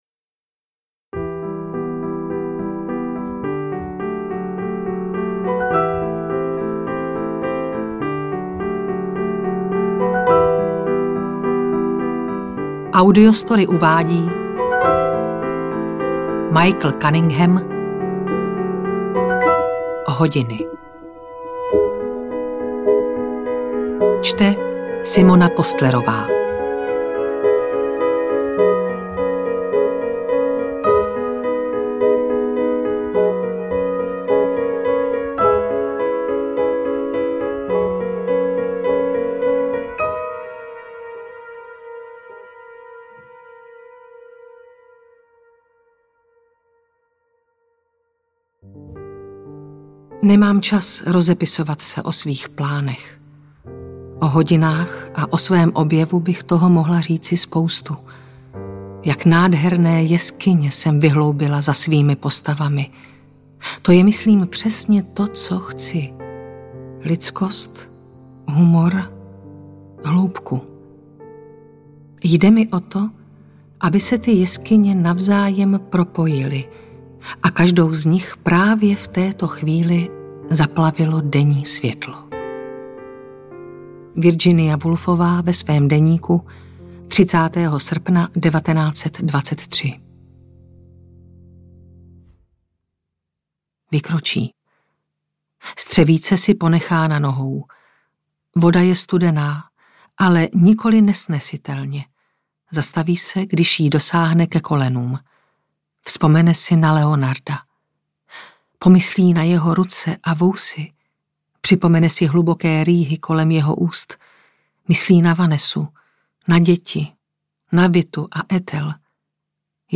Hodiny audiokniha
Ukázka z knihy
• InterpretSimona Postlerová